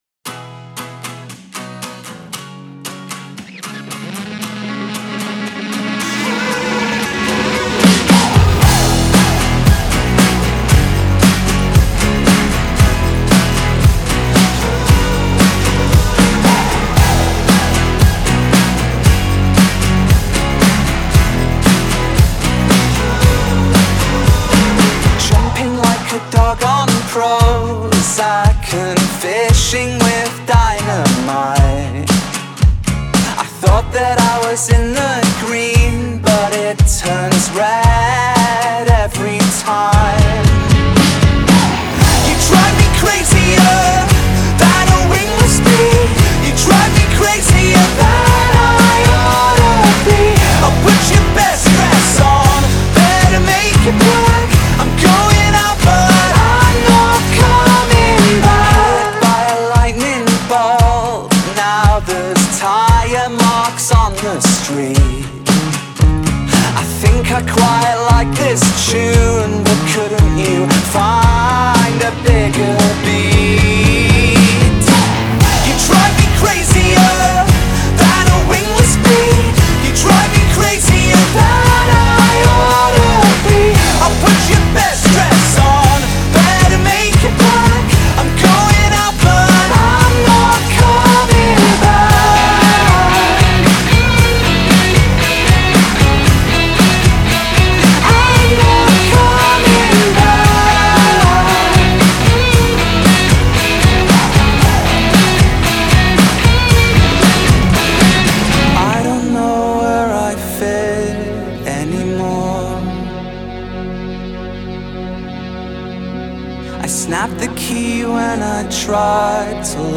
BPM115-115
Audio QualityPerfect (High Quality)
Indie Rock song for StepMania, ITGmania, Project Outfox
Full Length Song (not arcade length cut)